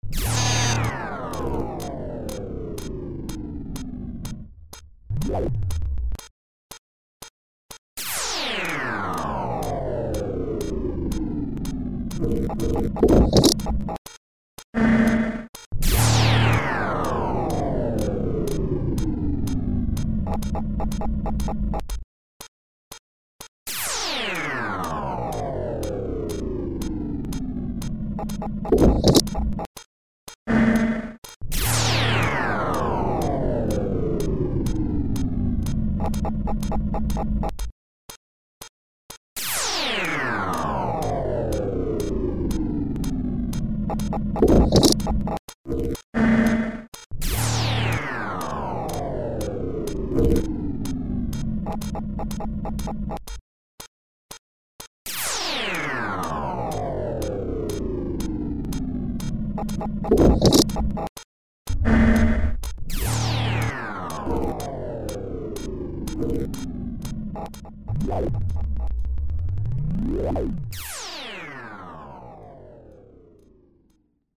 These were then scanned and the noises made by the scanner preserved as audio files, which the composer manipulated in the computer to build tracks.